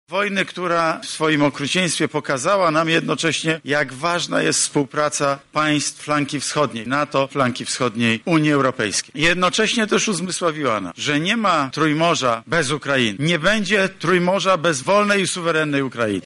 W obliczu wojny, wagę naszego wschodniego sąsiada w dalszej współpracy i przyszłości trójmorza podkreślił premier Morawiecki.